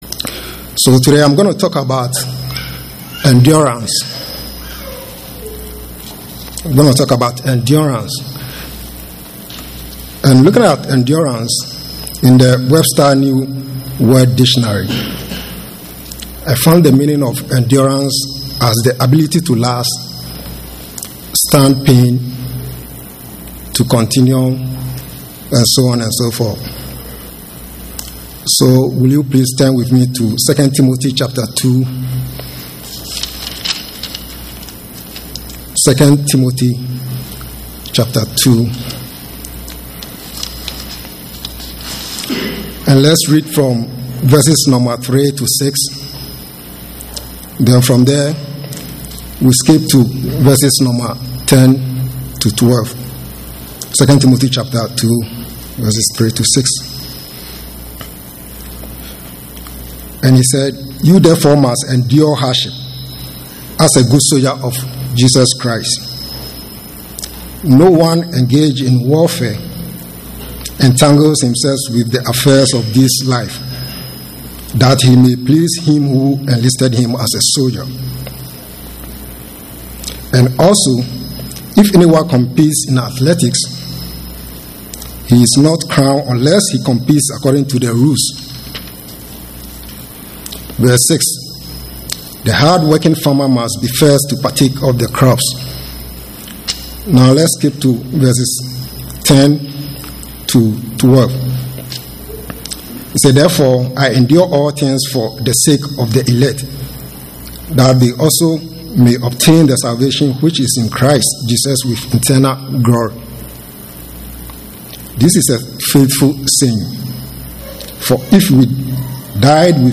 UCG Sermon Studying the bible?
Given in Atlanta, GA